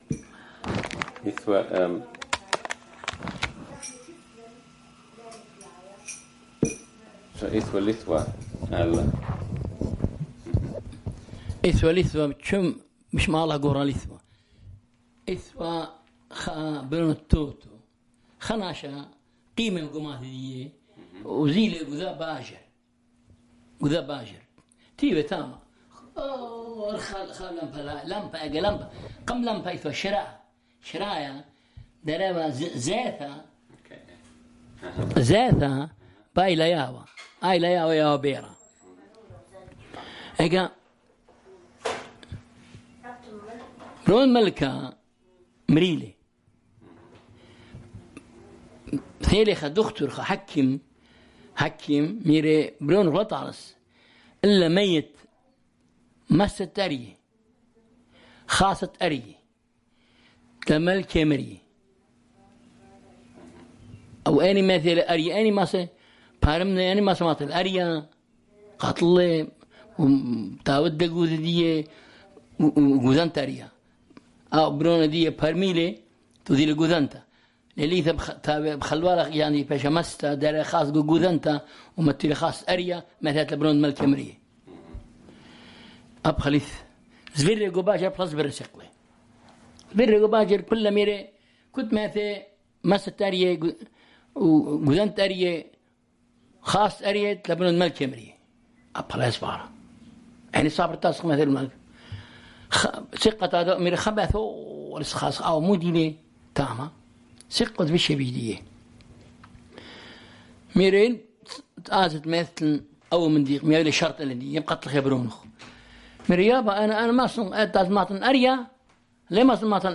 Barwar: The Lion With a Swollen Leg